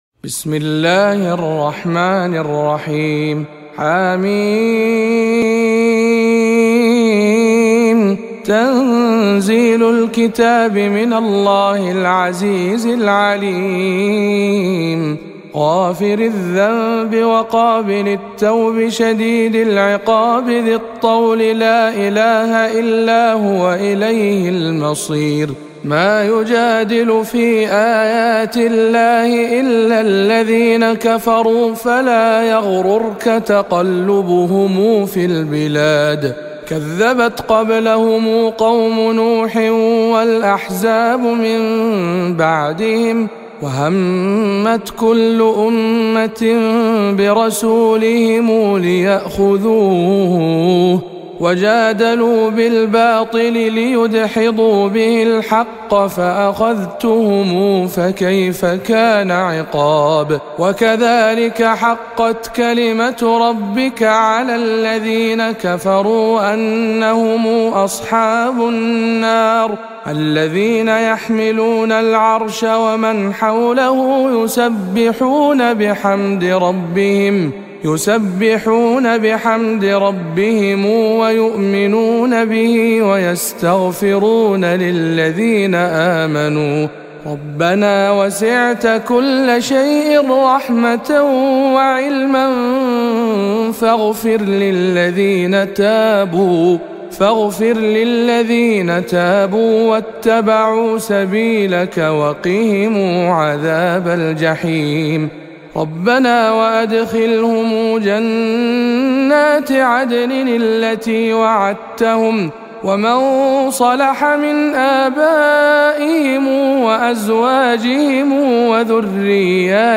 040. سورة غافربرواية قنبل عن ابن كثير